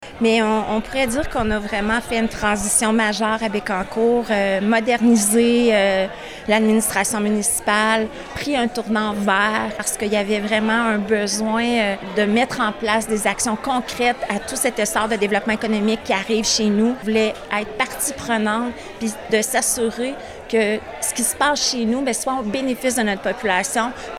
Pour l’occasion, les médias étaient invités au Manoir Bécancour.
Lors de son discours devant une soixantaine de personnes, elle a présenté ses orientations, dont miser sur la croissance humaine et durable, améliorer la qualité de vie des citoyens et dynamiser l’économie locale.